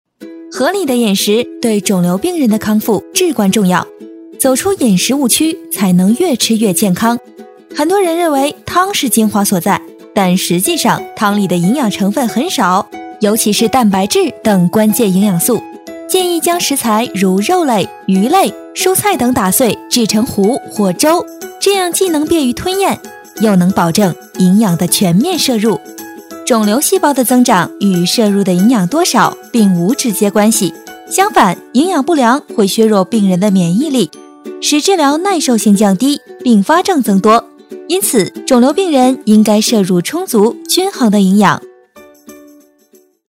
女配音-配音样音免费在线试听-第17页-深度配音网
女277-MG-科普知识
女277角色广告专题 v277
女277-MG-科普知识.mp3